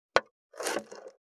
478切る,包丁,厨房,台所,野菜切る,咀嚼音,ナイフ,調理音,まな板の上,料理,
効果音厨房/台所/レストラン/kitchen食器食材